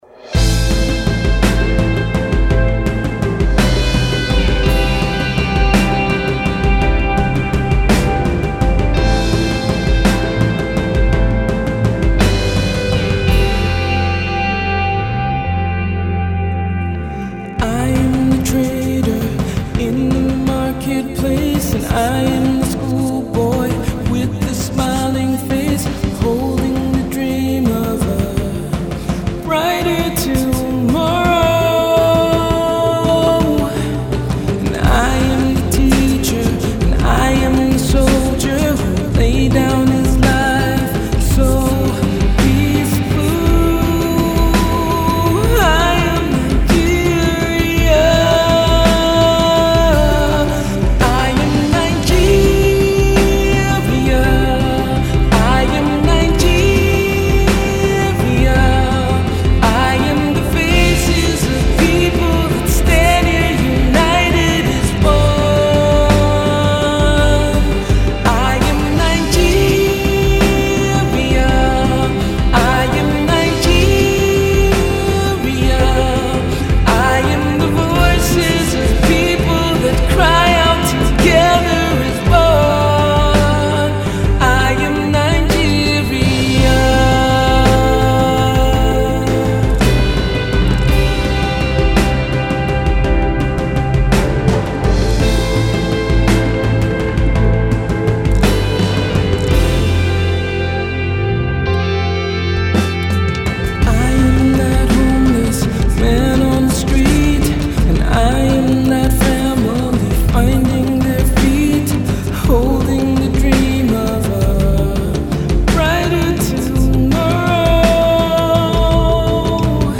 one of which is Nigeria’s very own prime indie band